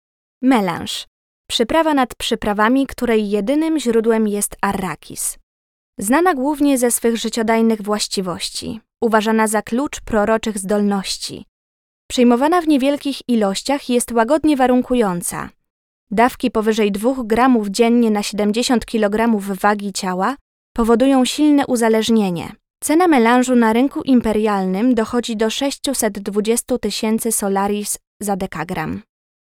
Female 5-20 lat
Nagranie do filmu instruktażowego